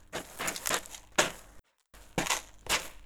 Digging.wav